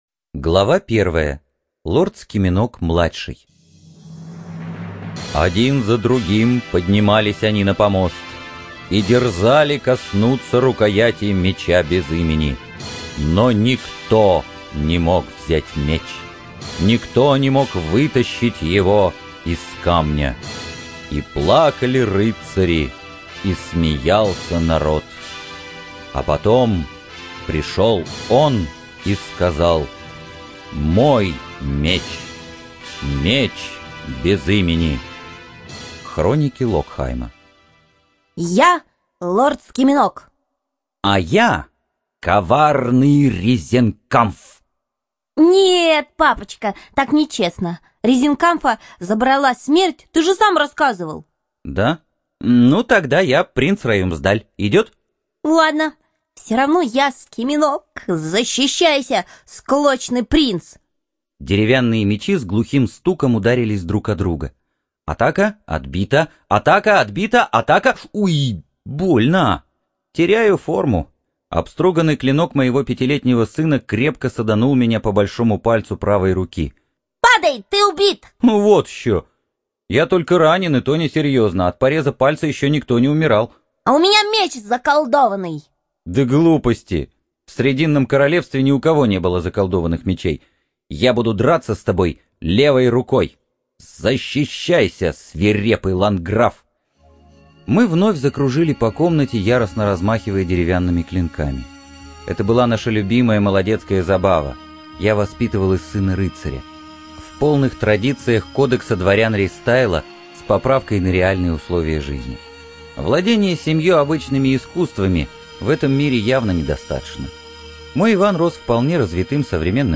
Отличный тандем чтецов!